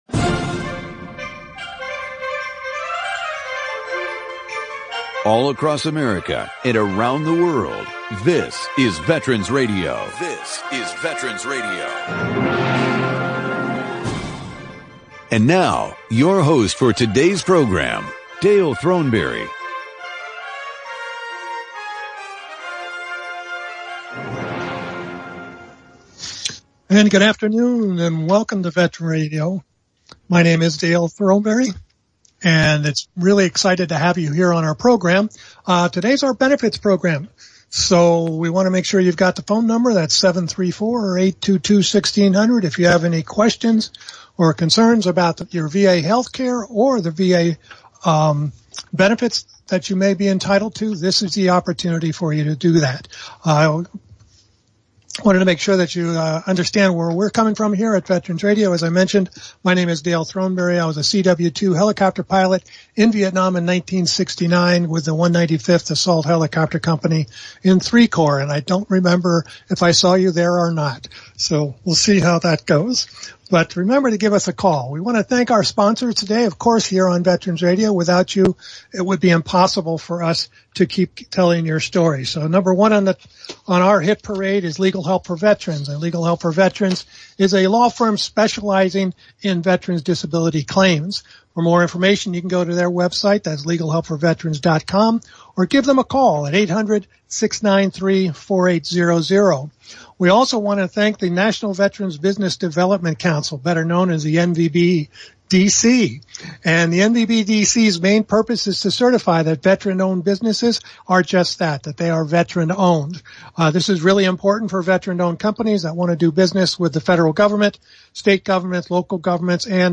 Veterans Benefits panel of legal counsel and veteran service office experts answer your questions.
This Week’s Program This is our monthly program on benefits, which happens on the last Sunday of each month.
Call in during the program to ask your question or offer a comment to our panel.